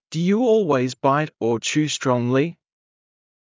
ﾄﾞｩ ﾕｳ ｵｰﾙｳｪｲｽﾞ ﾊﾞｲﾄ ｵｱ ﾁｭｰ ｽﾄﾛﾝｸﾞﾘｰ